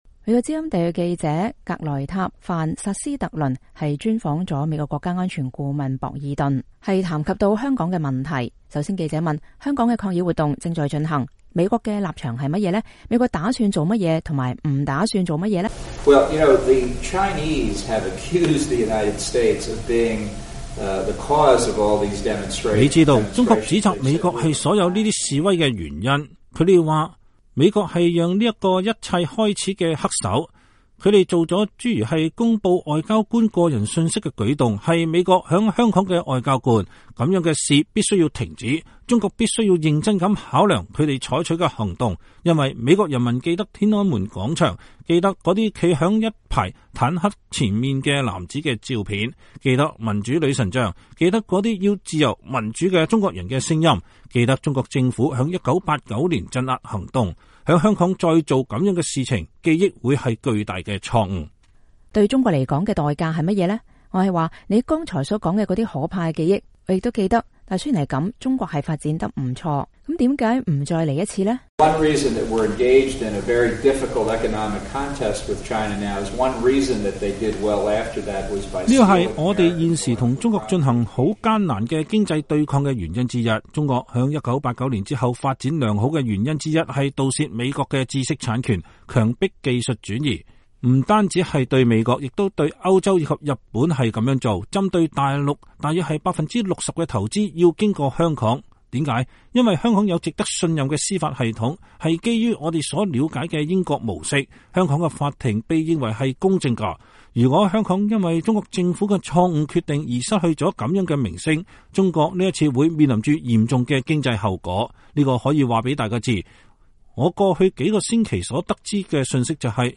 美國國家安全顧問博爾頓接受美國之音專訪談到香港問題。
VOA專訪美國國安顧問博爾頓：中國在香港再造天安門鎮壓的記憶將是巨大的錯誤。